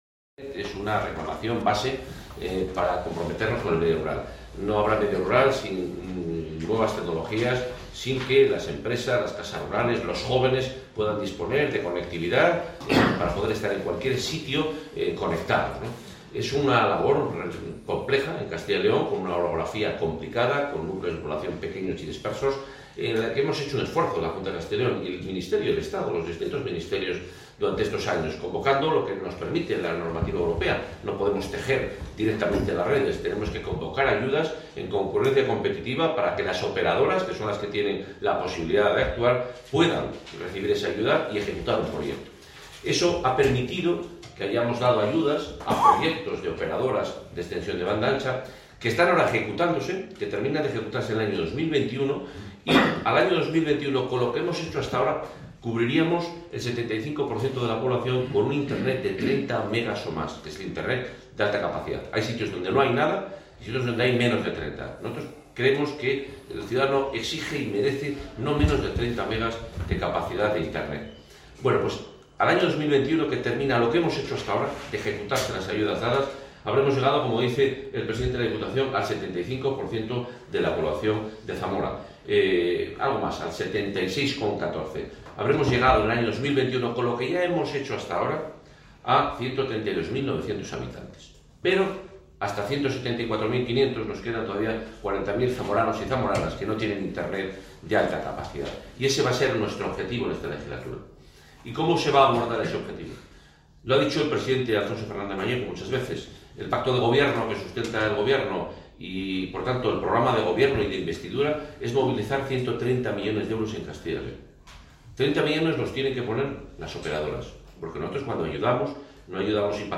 Audio consejero 2.